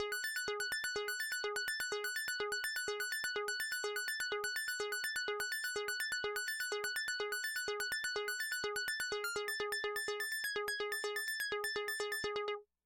Roland Juno 6 Loops 125bpm " Roland Juno 6 Loops 125bpm F6 (125 Loops90127 O84U
描述：通过Modular Sample从模拟合成器采样的单音。
标签： F6 midi-注意-90 罗兰朱诺-6 合成器 单票据 多重采样
声道立体声